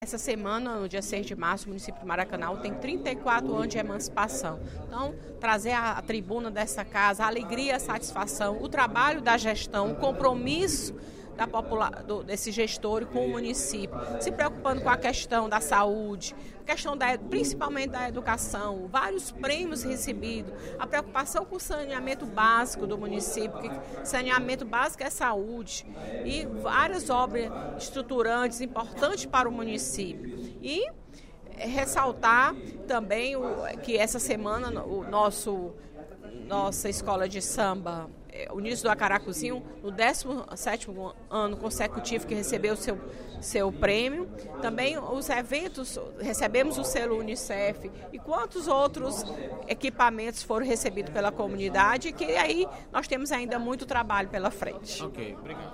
A deputada Fernanda Pessoa (PR) destacou, durante o primeiro expediente da sessão plenária desta quinta-feira (09/03), os 34 anos de emancipação do município de Maracanaú, transcorridos no último dia 6 de março. A parlamentar disse que representa a cidade na Assembleia Legislativa e tem muito respeito pelo município que representa.